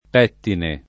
[ p $ ttine ]